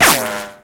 assets / hbm / sounds / weapon / ric2.ogg
ricochet sounds